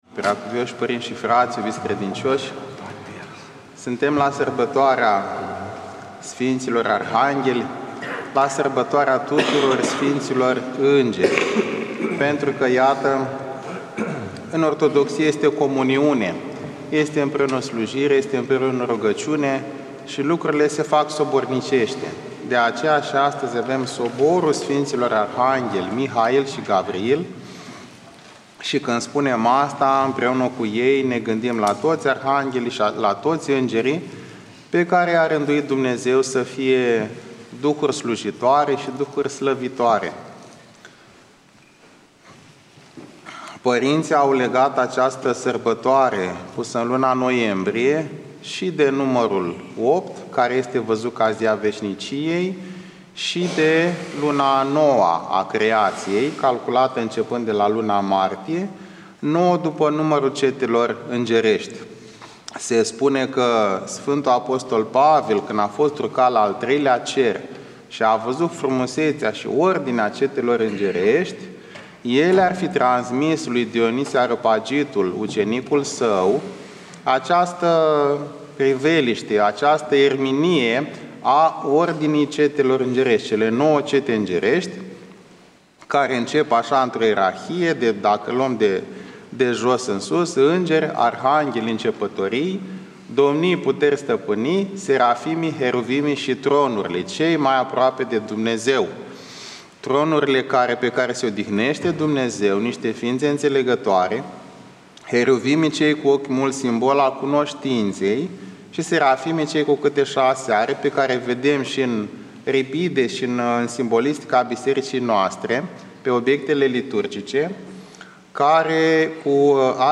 Predică PF Daniel